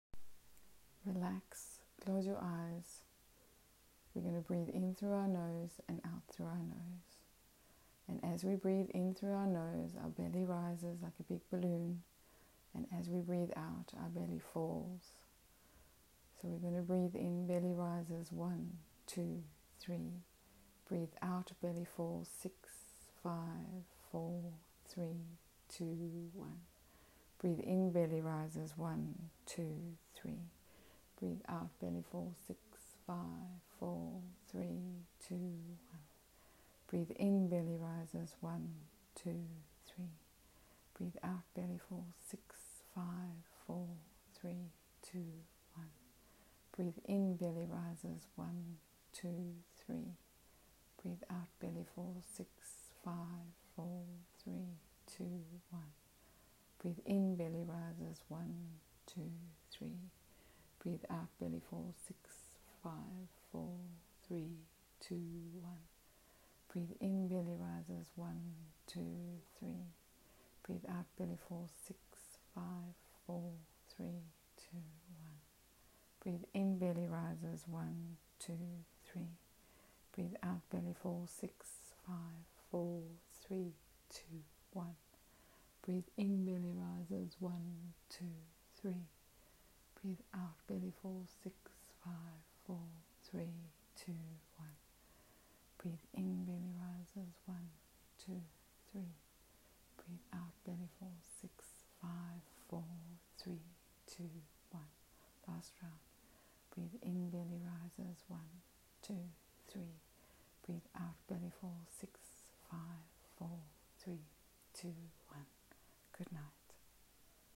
This guided diaphragmatic breathing technique is great for those who struggle to fall asleep including kids. If you have had a big day, perhaps anxious or feeling overwhelmed, this breathing technique resets your autonomic nervous system into a parasympathetic state (rest and digest).